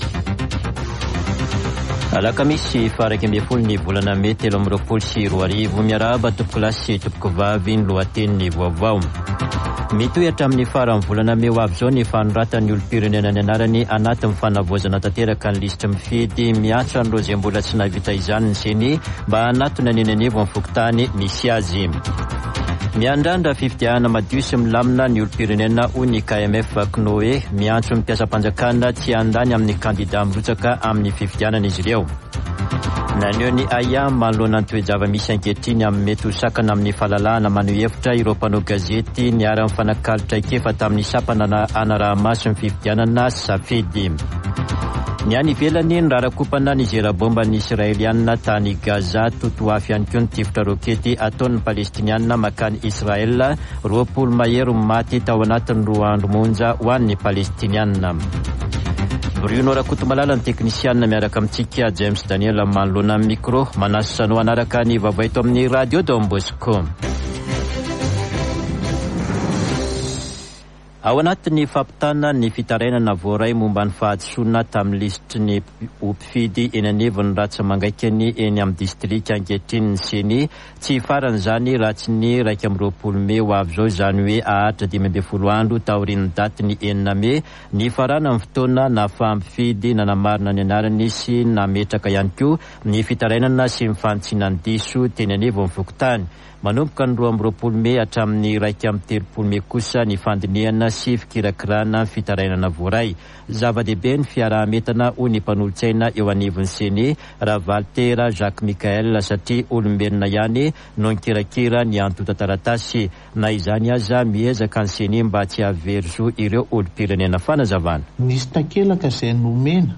[Vaovao maraina] Alakamisy 11 mey 2023